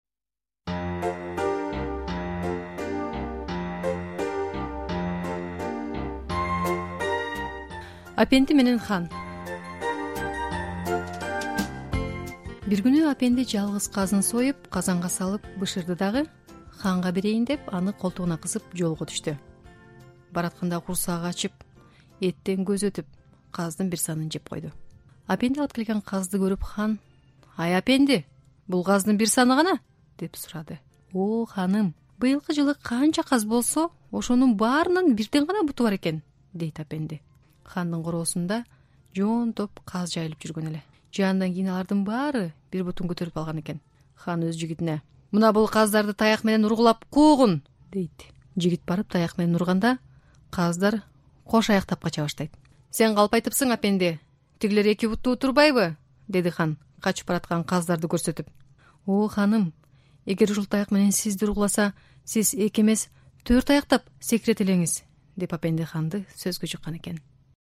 "Апенди менен Хан" Окуган